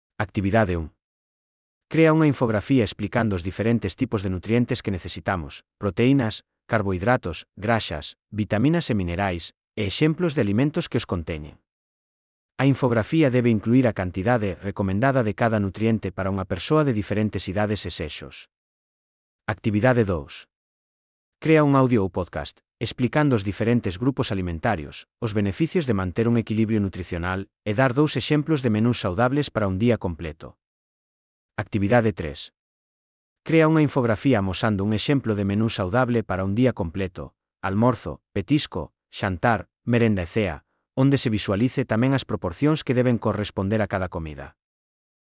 Transcrición de texto a audio dos exercicios Exercicio 1.